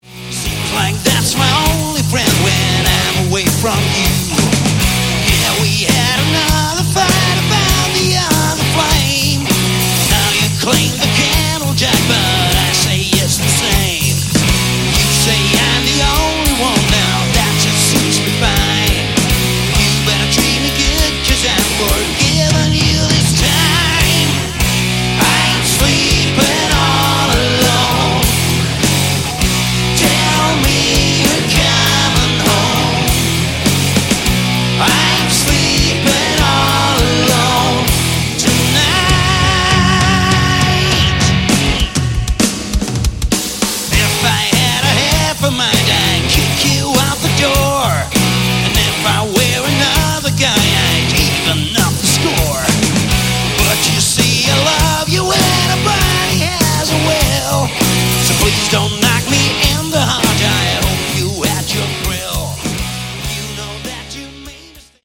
Category: Melodic Hard Rock
vocals, guitar
bass
drums